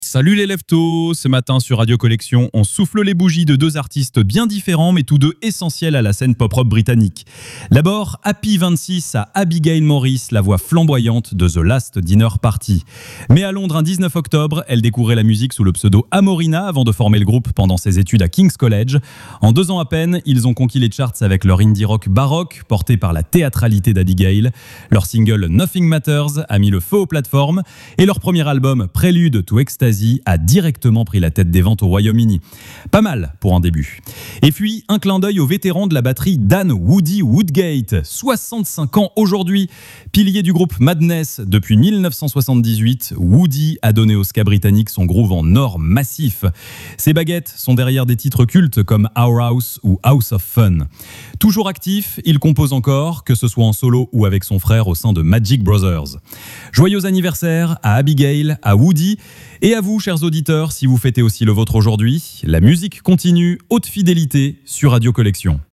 Vous écoutez la chronique Pop Rock de Radio Collection, la webradio gratuite et sans pub qui diffuse les plus grands classiques et les nouveautés en qualité Hi-Fi.